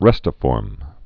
(rĕstə-fôrm)